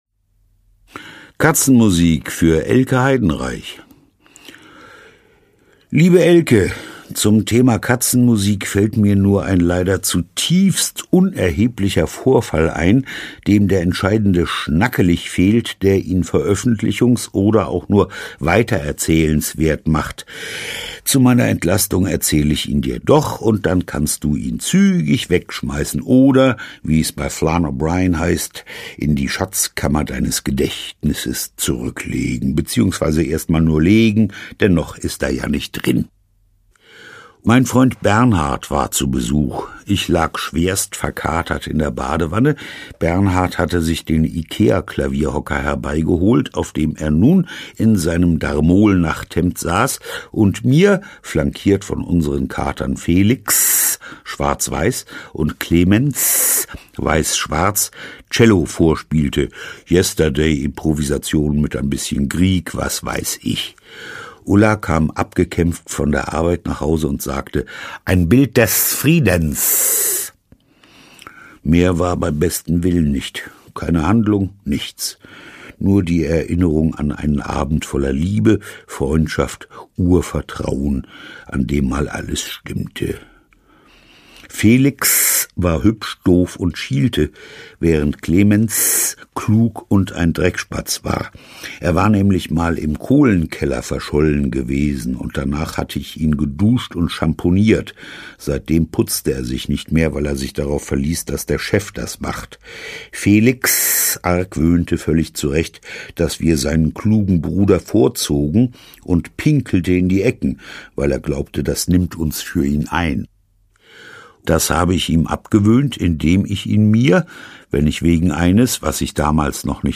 diverse (Sprecher)
Schlagworte Hörbuch; Literaturlesung • Kurzgeschichten • Kurzgeschichte / Short Story